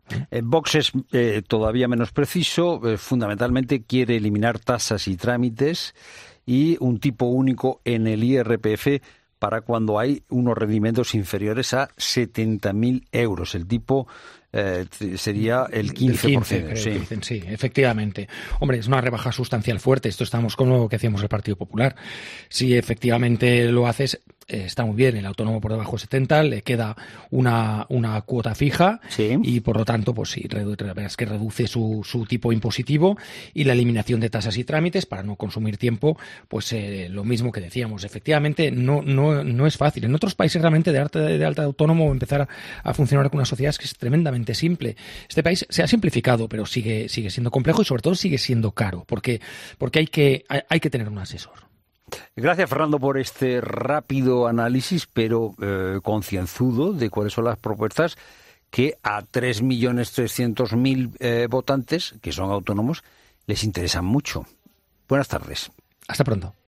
Análisis de Trias de Bes en La Tarde sobre las propuestas de VOX para los autónomos